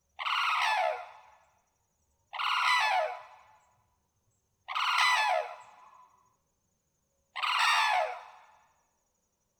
Guard Call | A sharp, single call expressing alarm.
Sarus-Crane-Alarm.mp3